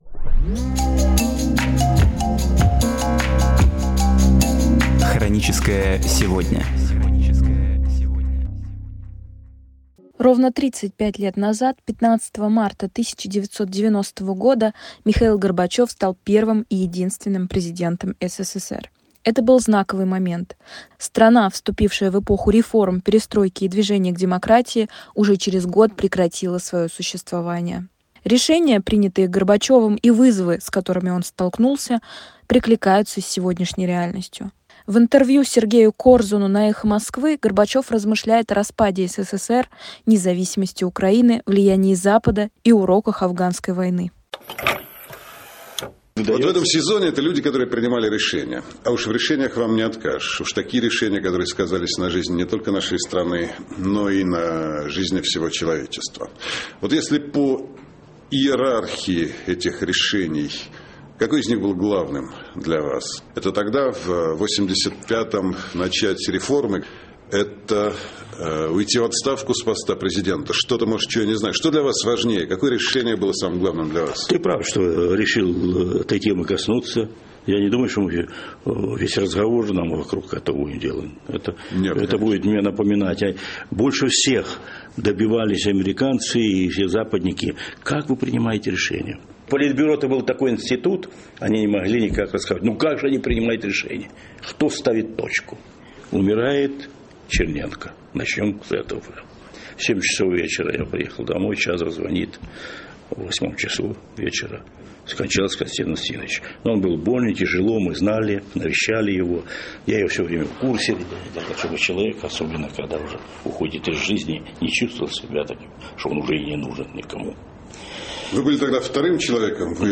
Архивная передача «Эха Москвы».